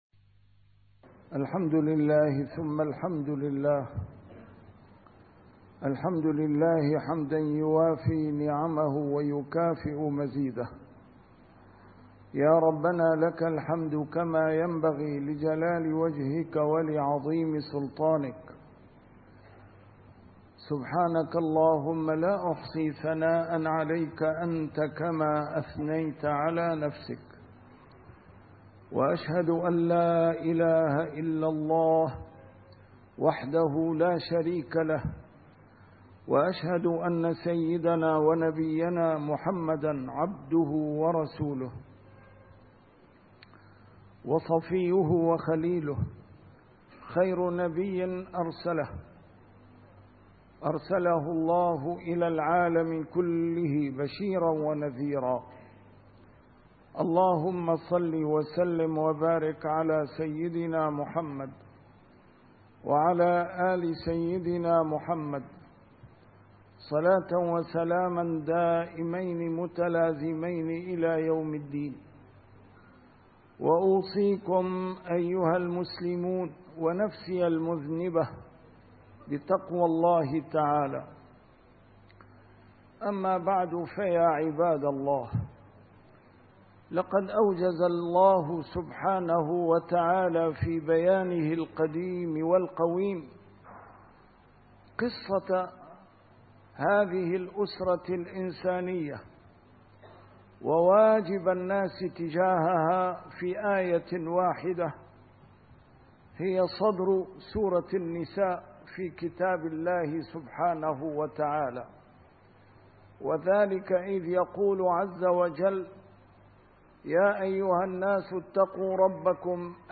نسيم الشام › A MARTYR SCHOLAR: IMAM MUHAMMAD SAEED RAMADAN AL-BOUTI - الخطب - لهذا نتقلب في مجتمع دجلٍ ونفاقٍ